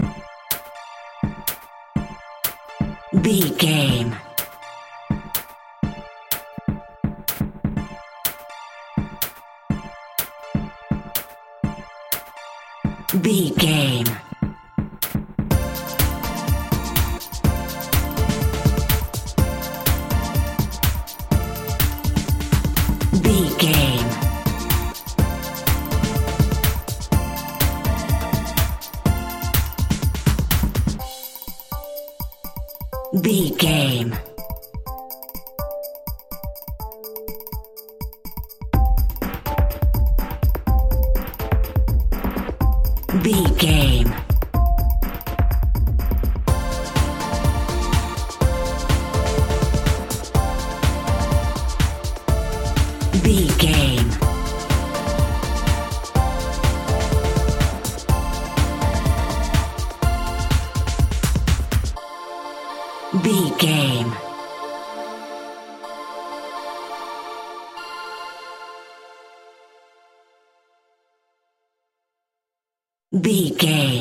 Aeolian/Minor
energetic
high tech
uplifting
futuristic
hypnotic
drum machine
synthesiser
Funk
funky house
disco funk
synth drums
synth leads
synth bass